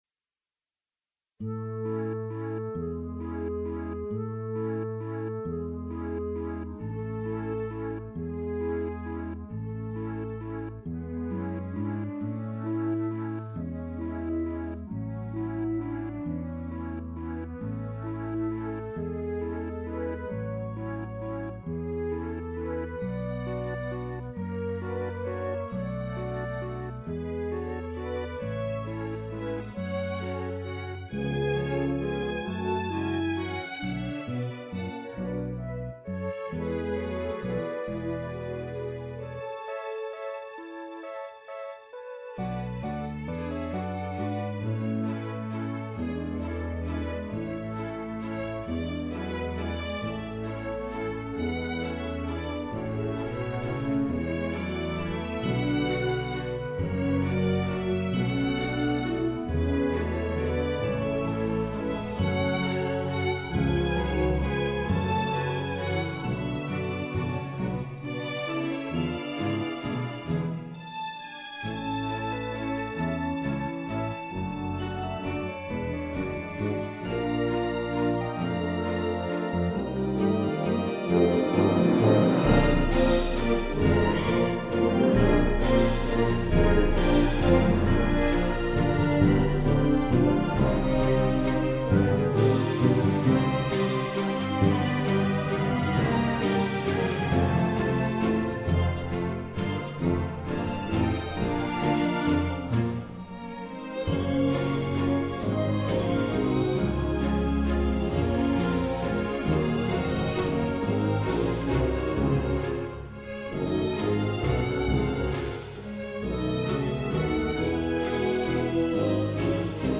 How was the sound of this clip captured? Done in the piano roll of Sonar 8.5.